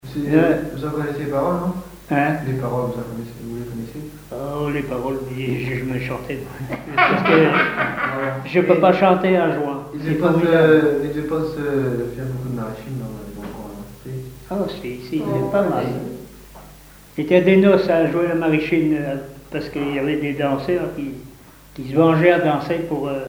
accordéon(s), accordéoniste ; musicien(s) ;
Catégorie Témoignage